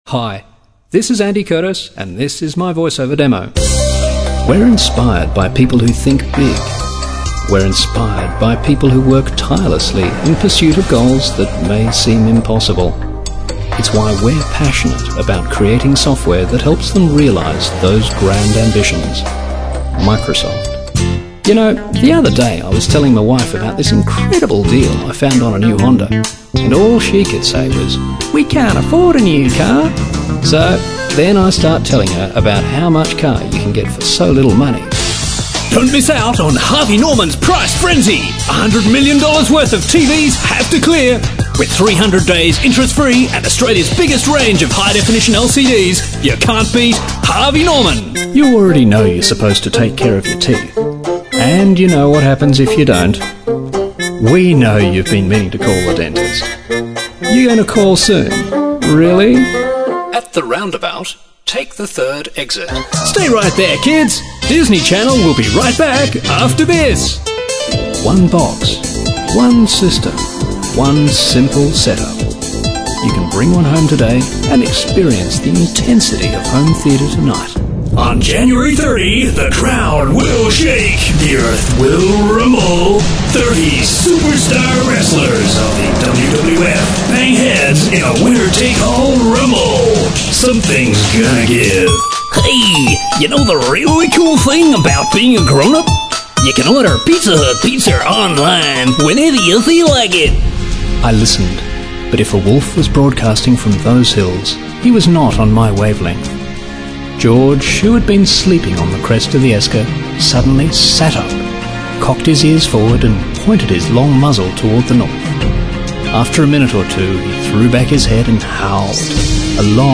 COMMERCIAL VOICEOVER
Voiceover Demo
Demo Reels